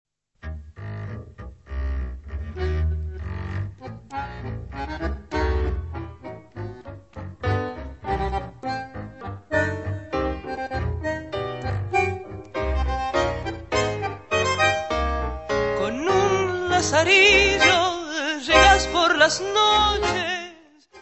Bandoneón
piano
contrabaixo
Music Category/Genre:  World and Traditional Music